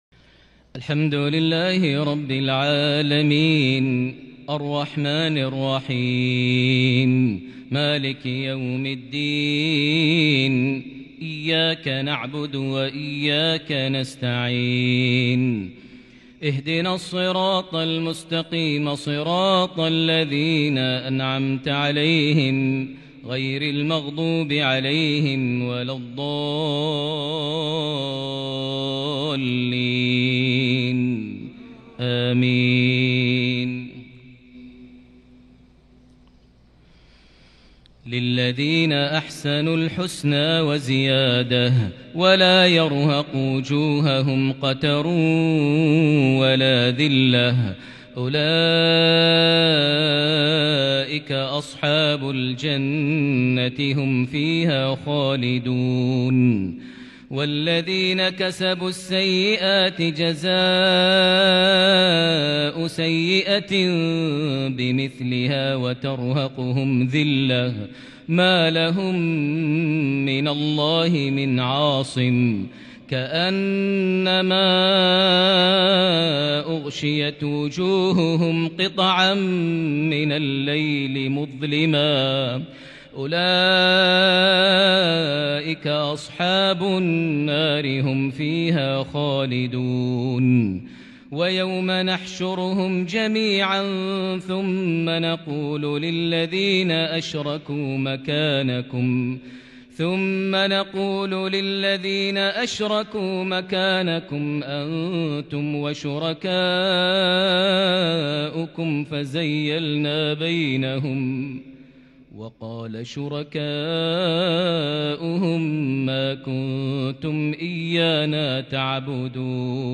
صلاة المغرب من سورة يونس 21 جمادى الآخر 1442هـ | mghrip 3-2-2021 prayer fromSurah Yunus 26-35 > 1442 🕋 > الفروض - تلاوات الحرمين